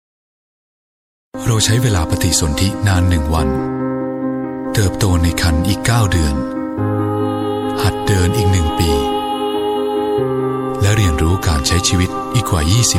• 9泰语男声4号